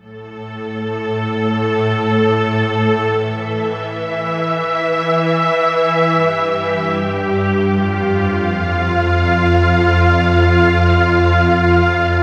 synth06.wav